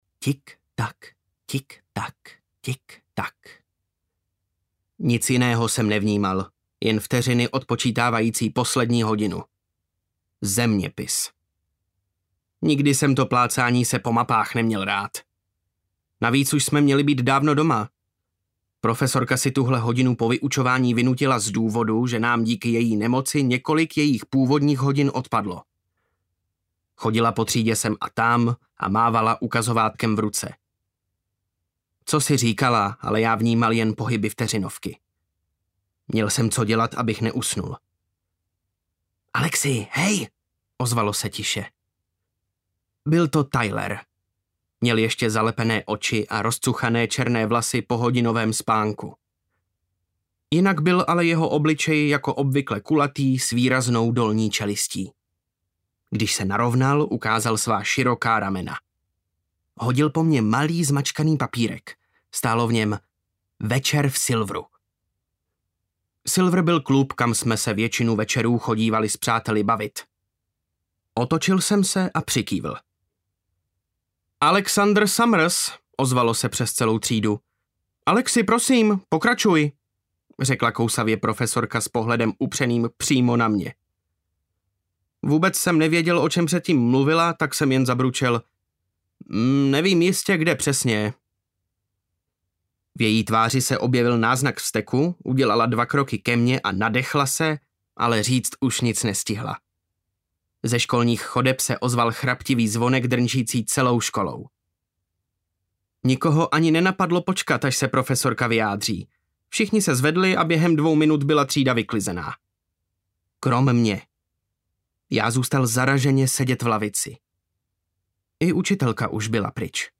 Elementum audiokniha
Ukázka z knihy
elementum-audiokniha